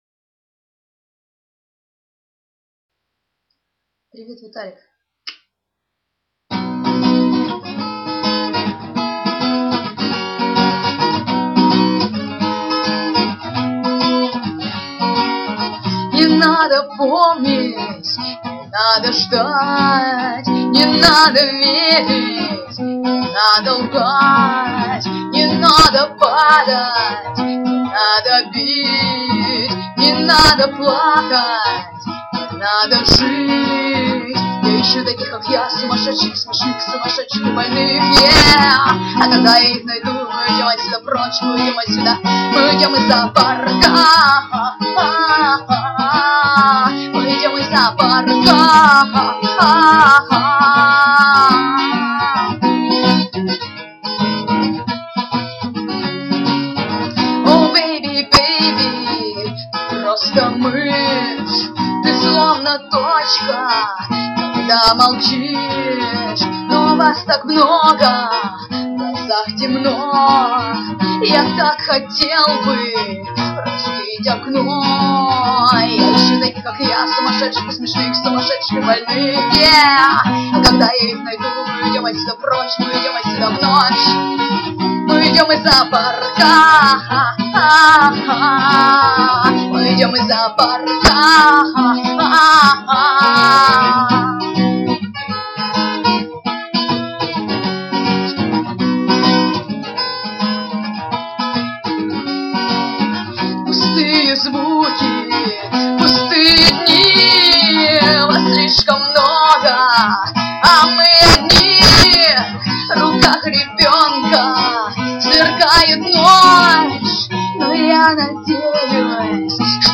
cover, request